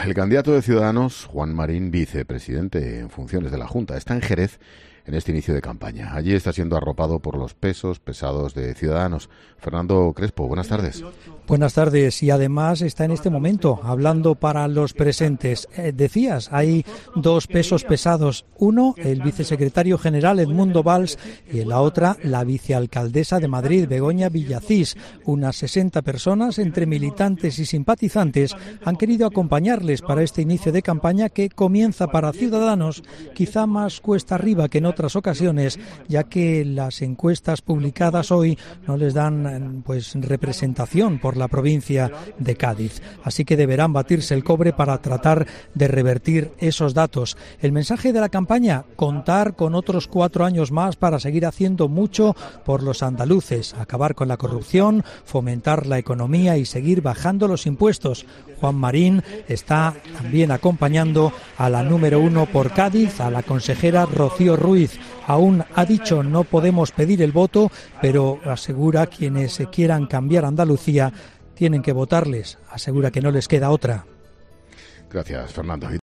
A las 19.00 horas estuvo en Jerez de la Frontera junto al vicesecretario general de su partido Edmundo Bal, y se trasladó después a otro mitin en Sevilla en el que estuvo arropado por la vicealcaldesa de Madrid Begoña Villacís a las 23.30 horas.